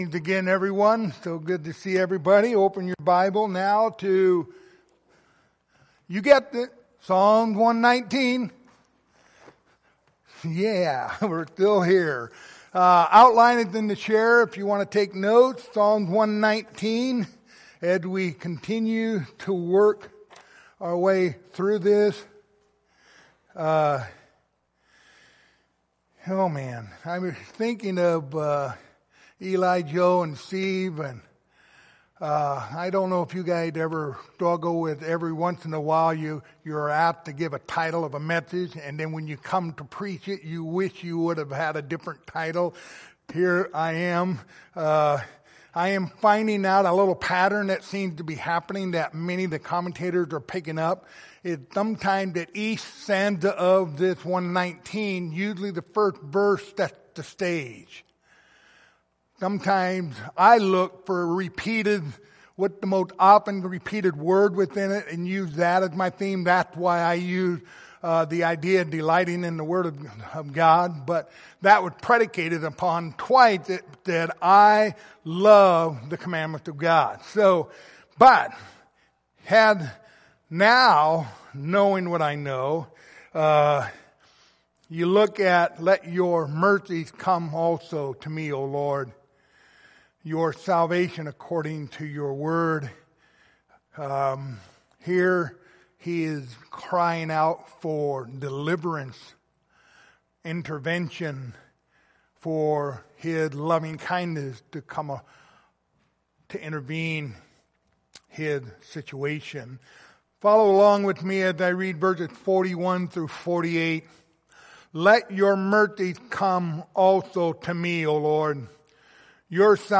Psalm 119 Passage: Psalms 119:41-48 Service Type: Sunday Evening Topics